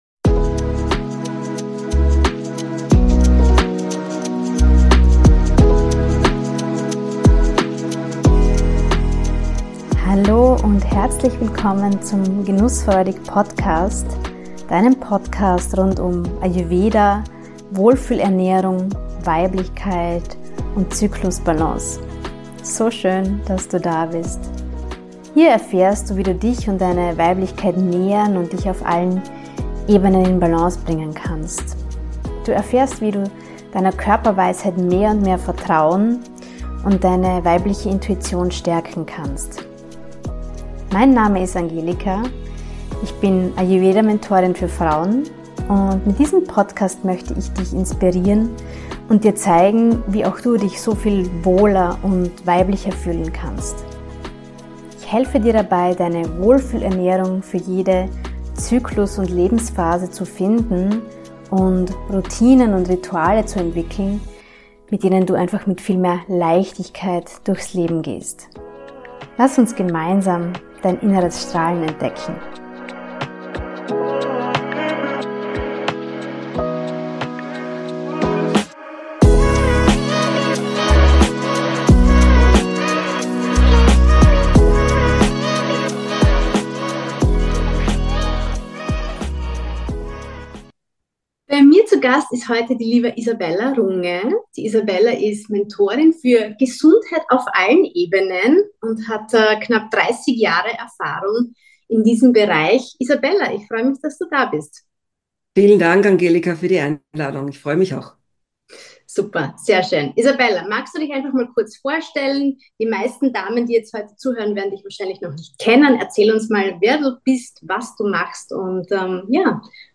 Healthy Aging & Hormonbalance: Was Frauen 40+ für ihre Gesundheit & Schönheit tun können – Interview